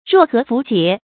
若合符节 ruò hé fú jié
若合符节发音